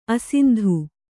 ♪ asindhu